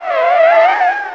tyre_skid_03.wav